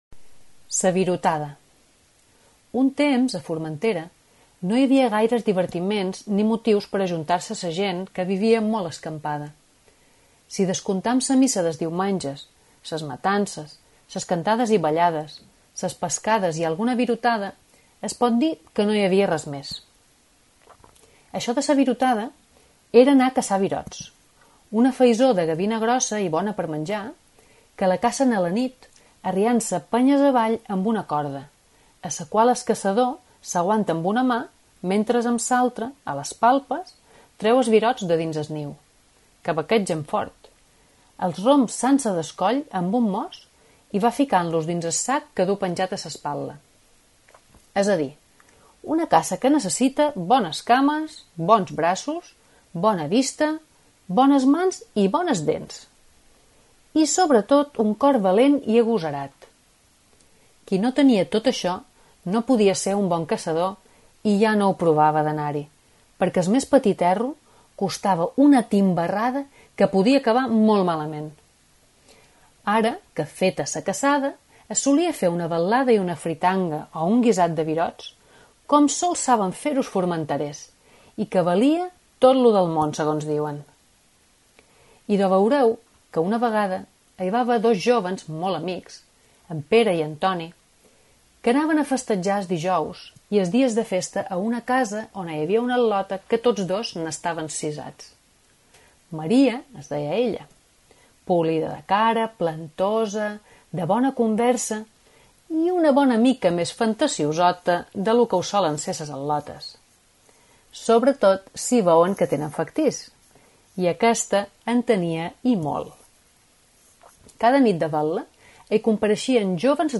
L’espai compta amb narració